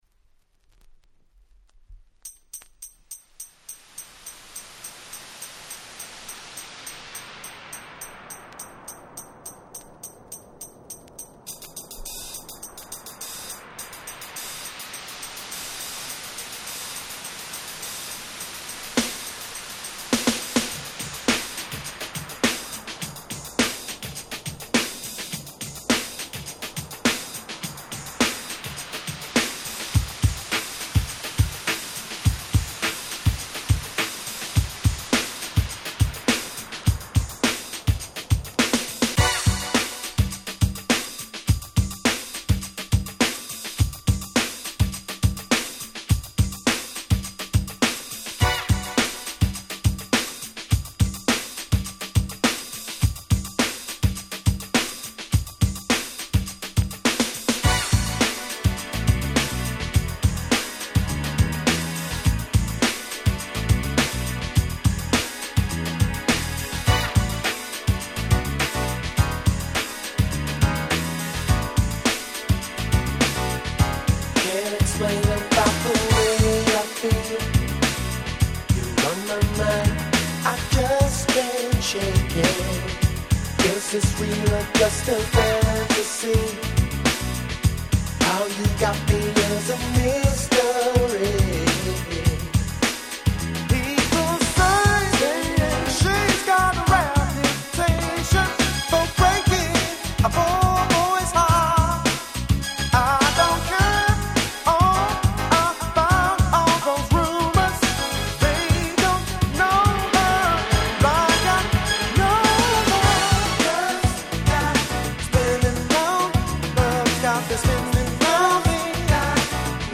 91' Nice UK Soul !!